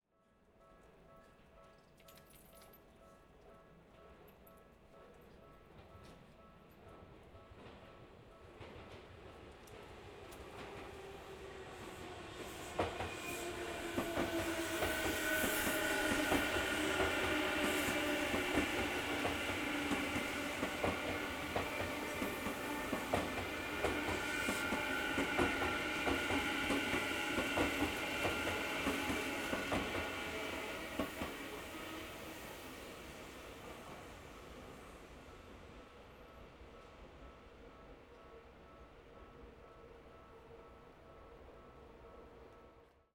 続いて同じ96kHzの設定のまま今度は線路沿い、踏切近くに移動して電車が通り過ぎるところを録音してみた。
それぞれで電車のスピードが違うので、雰囲気は違っているが、明らかに120°のほうが、広い音場空間になっていることに気が付くだろう。
H2essential_train_90.wav